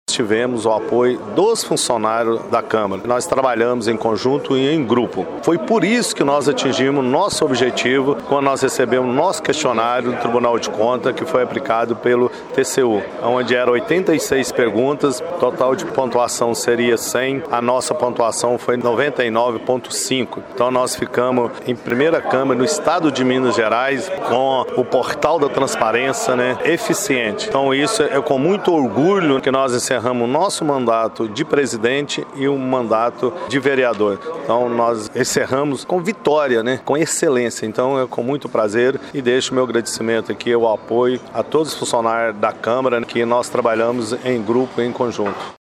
Em coletiva de imprensa, no Teatro Municipal, o Prefeito Elias Diniz fez uma apresentação das principais ações da administração municipal em 2024.